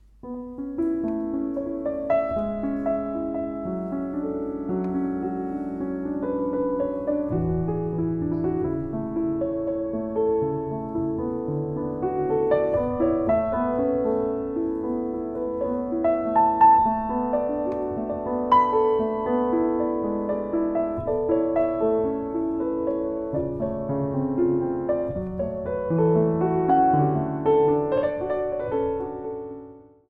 コンサートは、すべての曲がインプロヴィゼーション（即興）で演奏された。
夜の静けさと優しさとを感じさせる佳曲である。